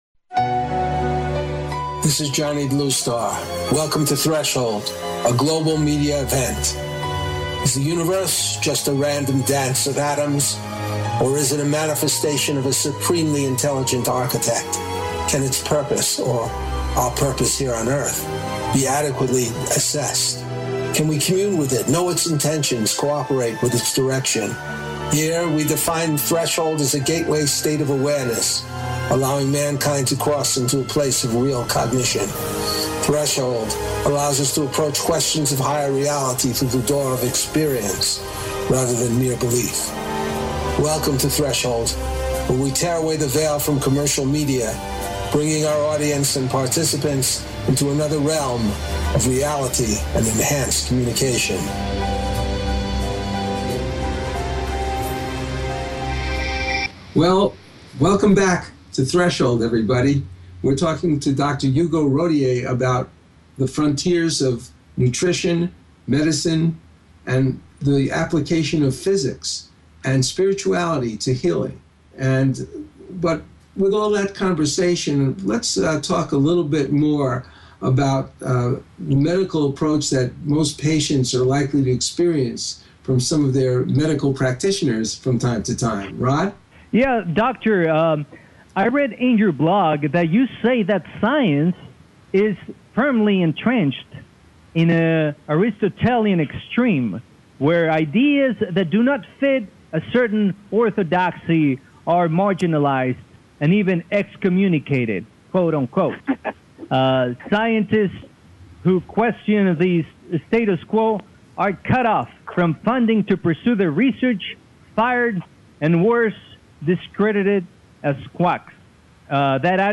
Threshold Radio is a radio talk show postulating that personal connection to a Higher Power is the most profitable way of being connected to your life, your destiny and to the Source whose purpose is embodied in the nature of things.